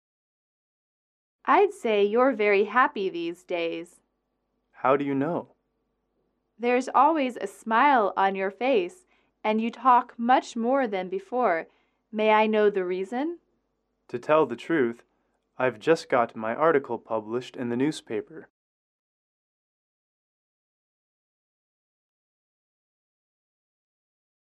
英语主题情景短对话24-4：发表文章(MP3)